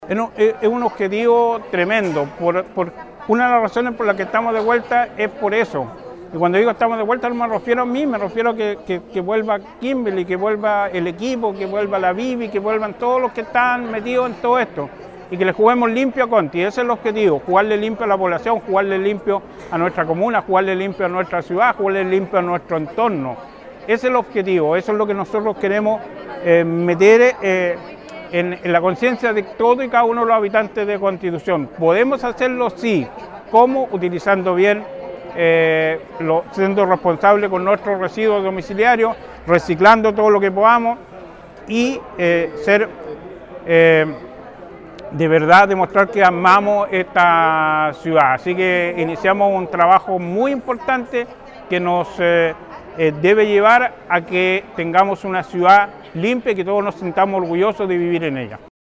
Este martes, en el Hall del Teatro Municipal, se llevó a cabo el lanzamiento del Plan de Gestión de Residuos Clasificados, una iniciativa impulsada por el Departamento de Medio Ambiente de la Dirección de Aseo, Ornato y Medio Ambiente.
CARLOS VALENZUELA – ALCALDE CONSTITUCION